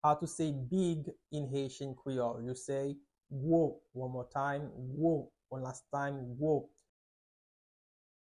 Pronunciation:
3.How-to-say-Big-in-Haitian-Creole-–-Gwo-pronunciation.mp3